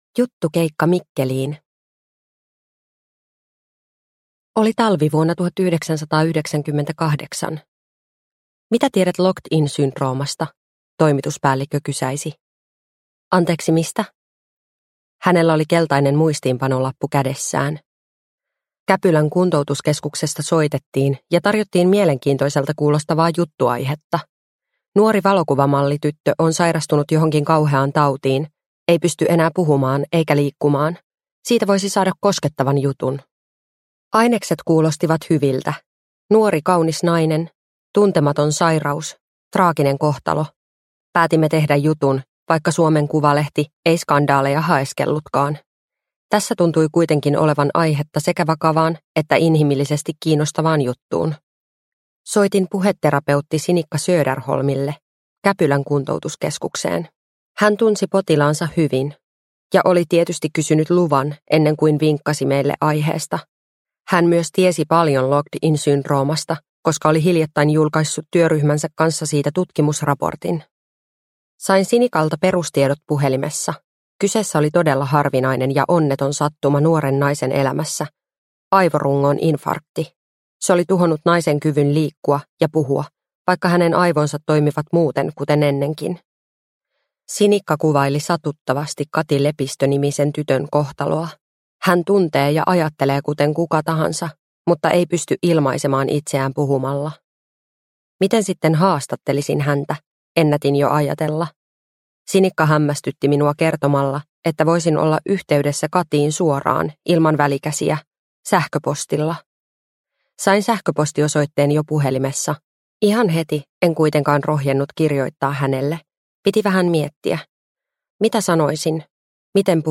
Silmänräpäys – Ljudbok – Laddas ner